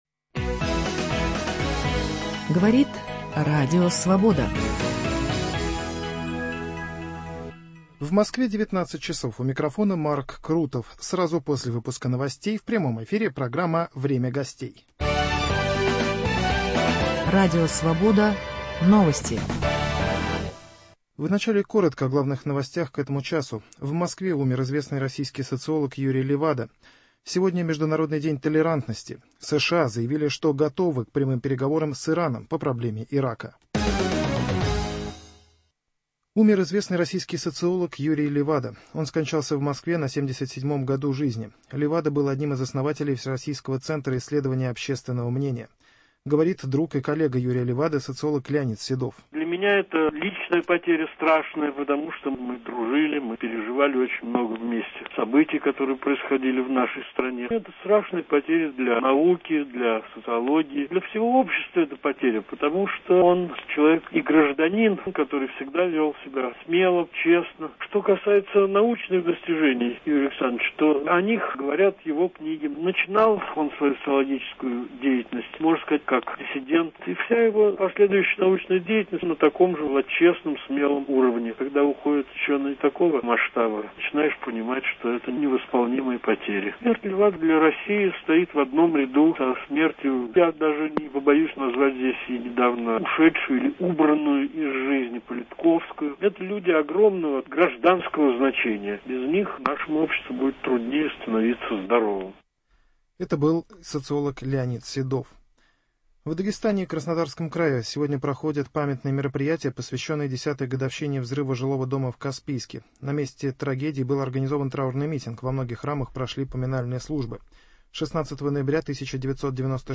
Можно ли считать современную молодежь интеллегентной. Гость: Профессор, глава Исполкома петербургской интеллегенции, ректор гуманитарного университета профсоюзов Александр Запесоцкий.